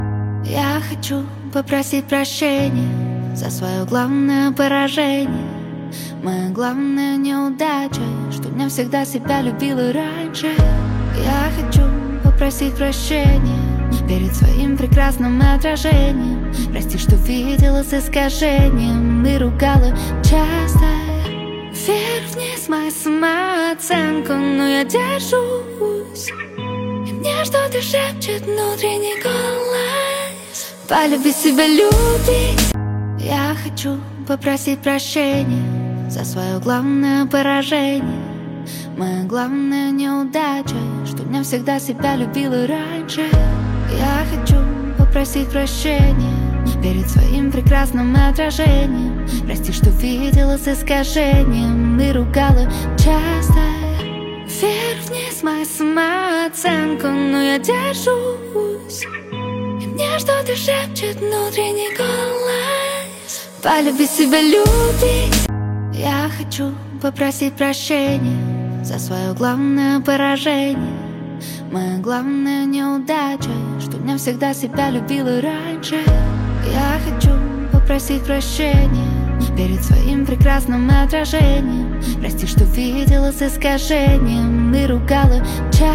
Поп музыка, Русские треки